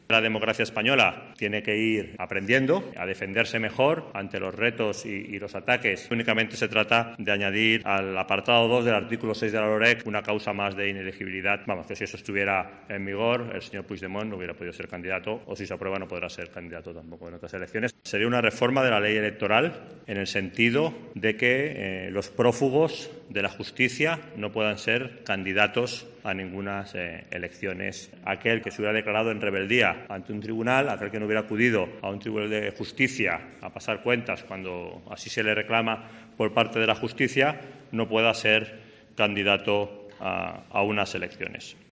En declaraciones en el Congreso el diputado ha defendido que la Justicia "tiene que ir aprendiendo a defenderse mejor ante aquellos que quieren atacarla".